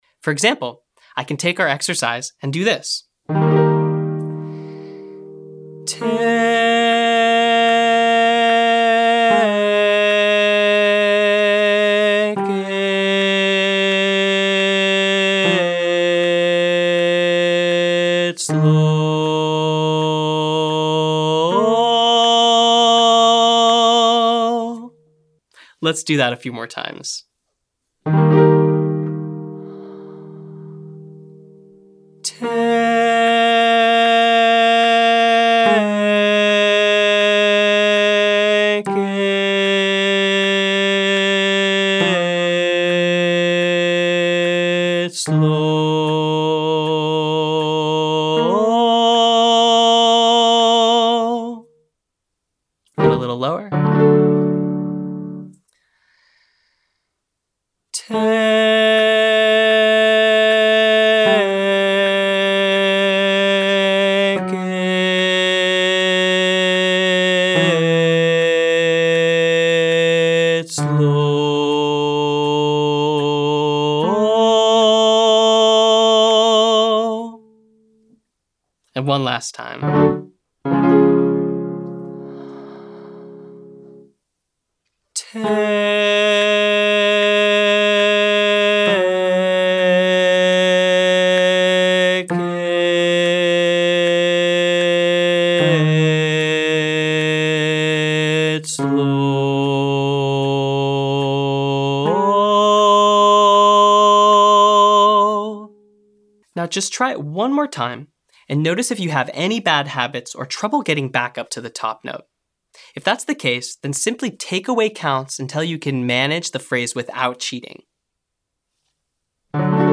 Singing Longer Phrases - Online Singing Lesson